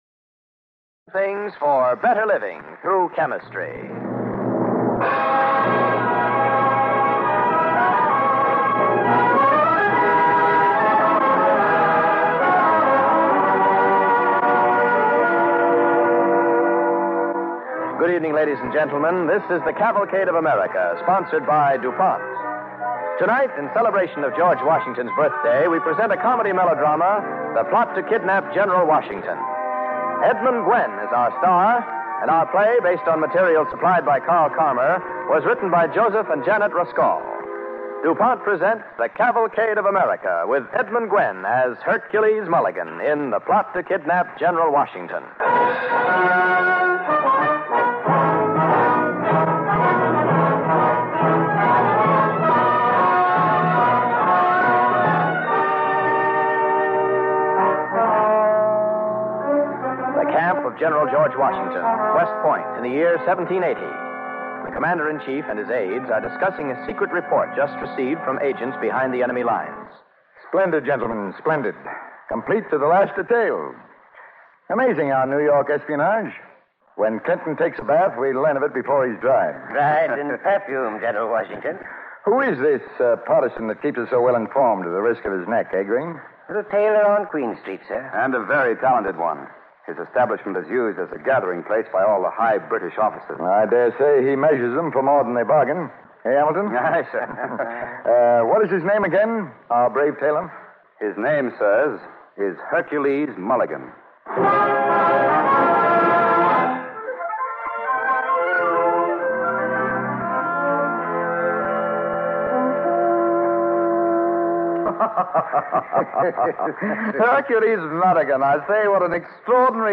Cavalcade of America Radio Program
starring Edmund Gwenn